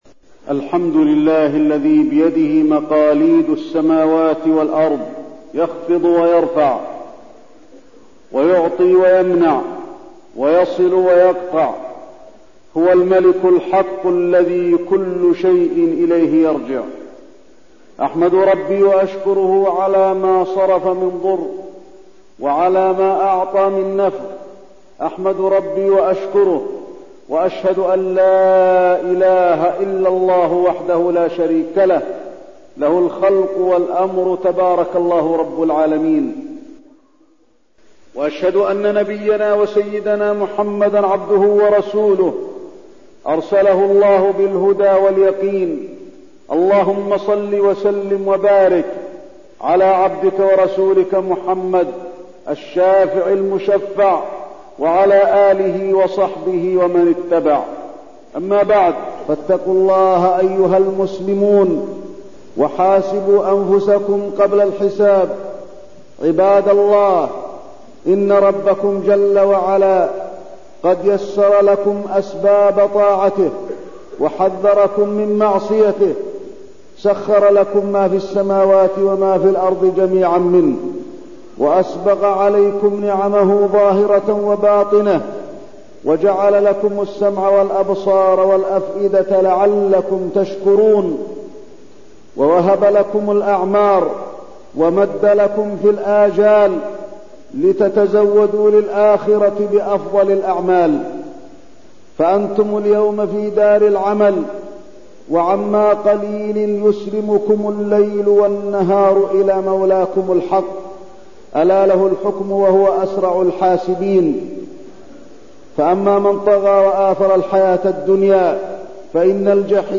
تاريخ النشر ٥ محرم ١٤١٤ هـ المكان: المسجد النبوي الشيخ: فضيلة الشيخ د. علي بن عبدالرحمن الحذيفي فضيلة الشيخ د. علي بن عبدالرحمن الحذيفي اغتنام الأوقات والهجرة النبوية